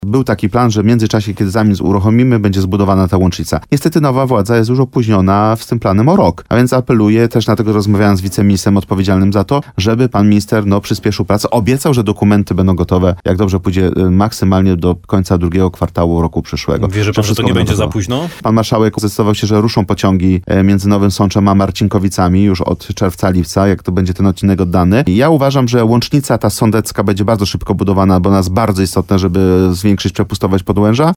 – Bardzo dobrze, że projekt rusza – mówił poseł RP Patryk Wicher w programie Słowo za Słowo w radiu RDN Nowy Sącz.